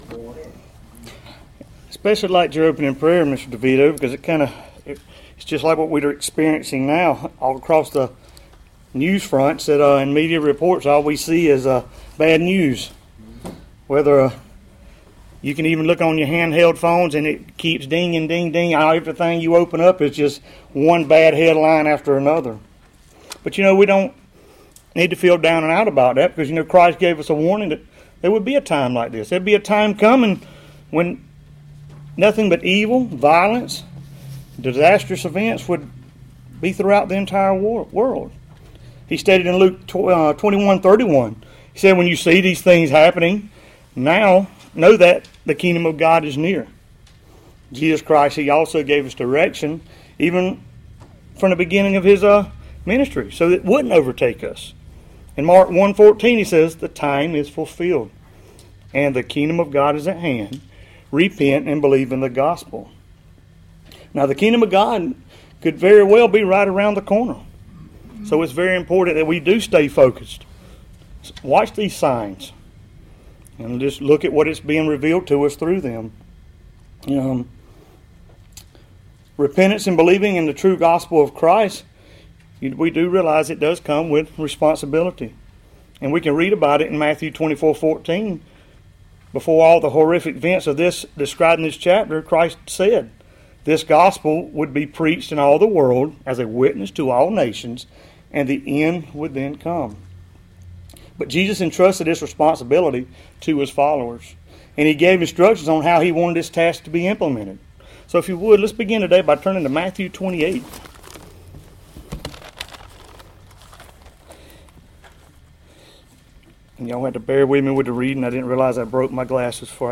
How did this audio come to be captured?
Given in Columbus, GA Central Georgia